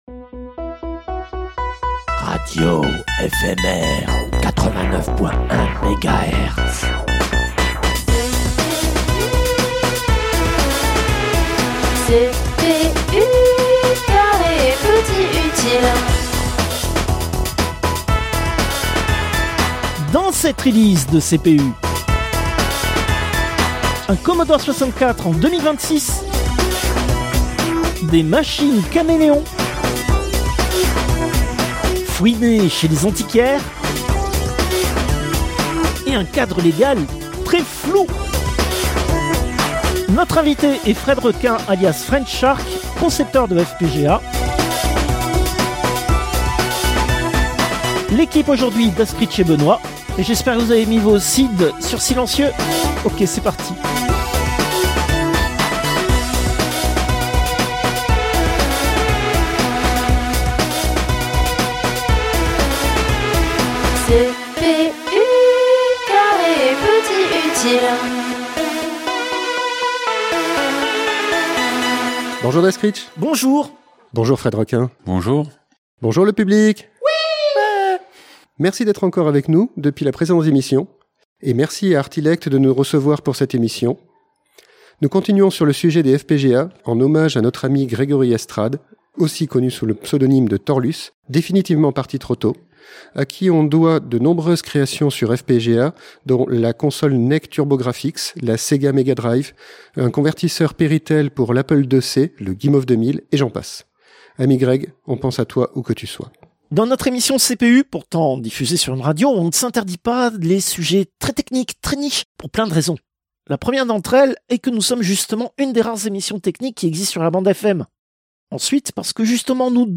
Interview, sixième partie